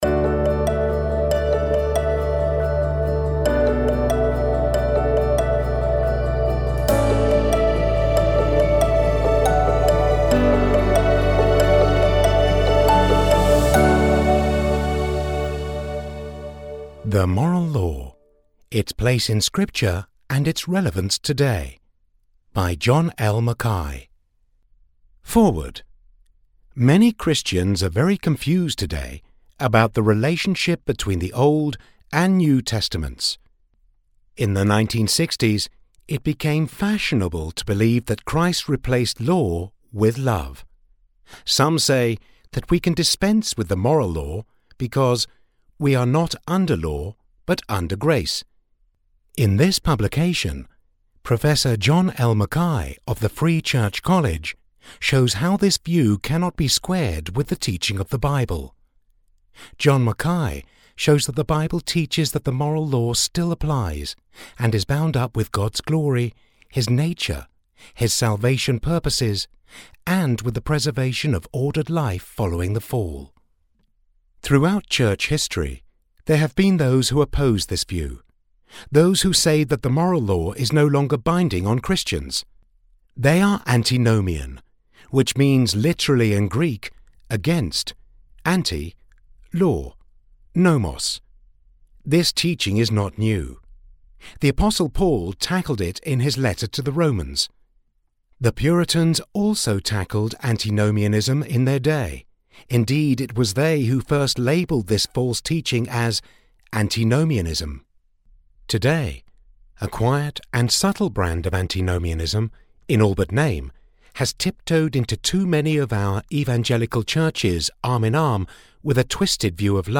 the-moral-law-audiobook.mp3